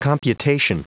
Prononciation du mot computation en anglais (fichier audio)
Prononciation du mot : computation